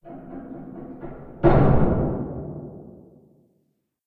scpcb-godot/SFX/Door/Door2Open1_dist.ogg at c2afe78d7f60ab16ee83c3070b724c6066b420c6
Door2Open1_dist.ogg